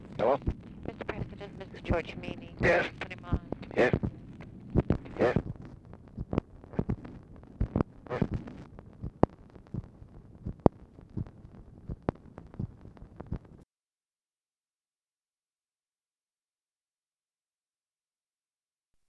TELEPHONE OPERATOR ANNOUNCES CALL FROM GEORGE MEANY
Conversation with TELEPHONE OPERATOR
Secret White House Tapes